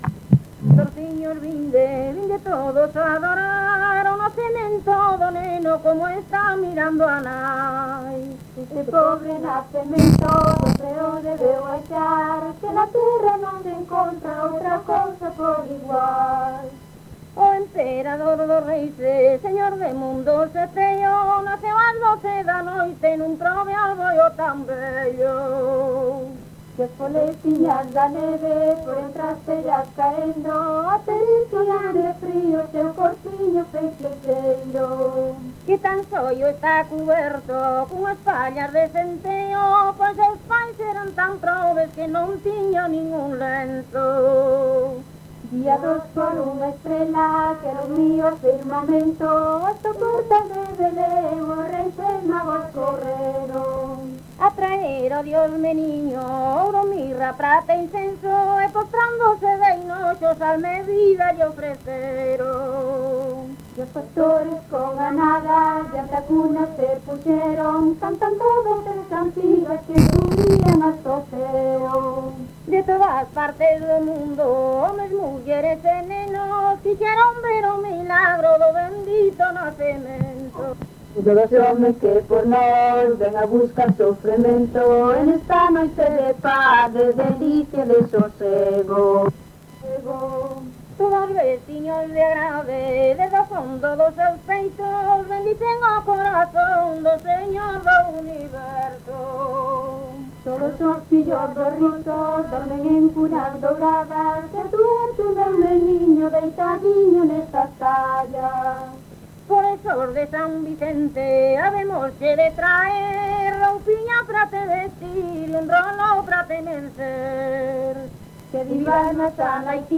Tipo de rexistro: Musical
Áreas de coñecemento: LITERATURA E DITOS POPULARES > Cantos narrativos
Soporte orixinal: Casete
Datos musicais Refrán
Instrumentación: Voz
Instrumentos: Voz feminina